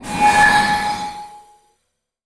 magic_shine_light_spell_01.wav